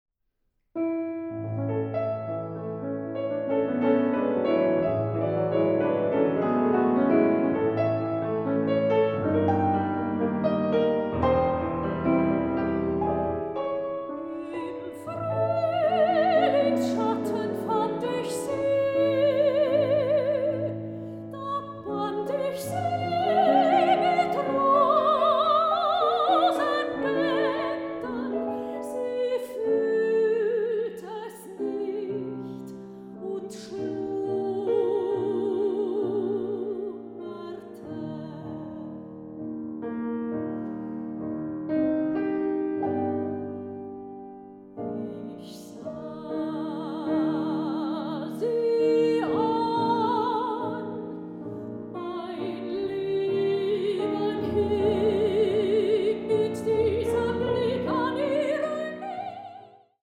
Sopran
Klavier
Aufnahme: Ackerscheune, Kulturstiftung Marienmünster, 2025